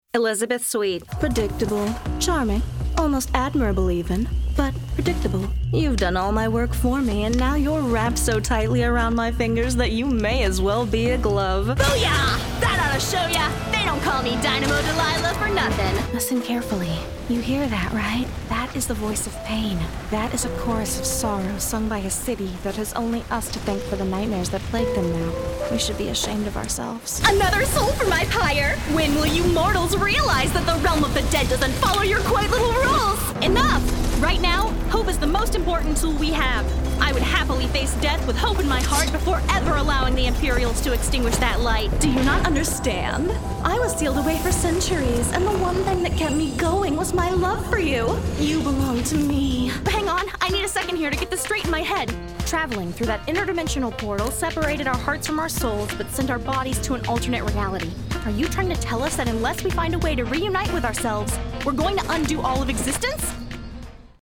Voice Actor
DEMO REEL⭐